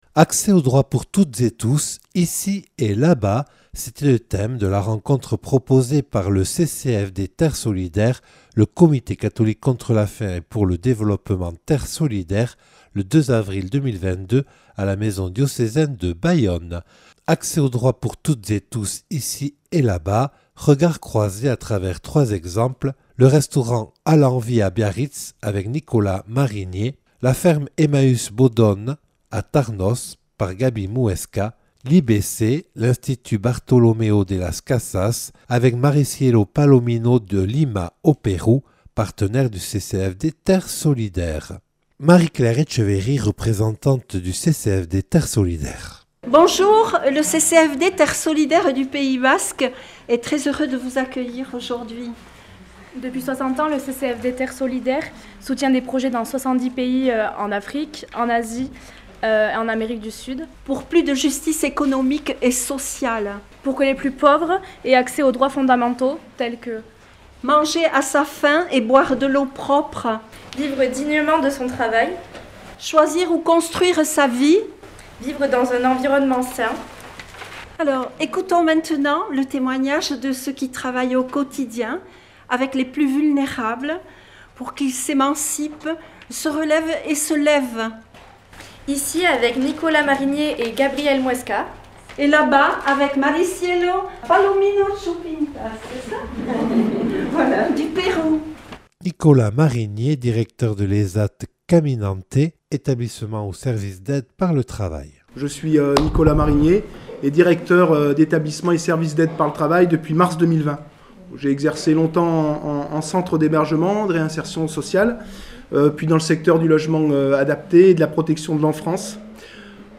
Table-ronde du CCFD-Terre Solidaire le 2 avril 2022 à la maison diocésaine de Bayonne.